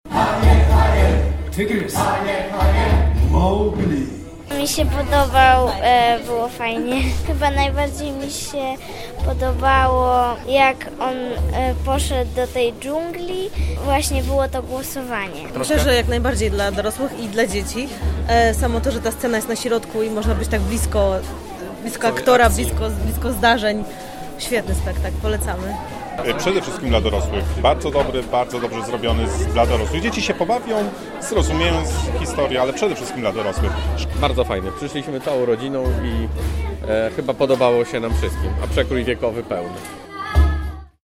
Naszych reporter spytał widzów o wrażenia po spektaklu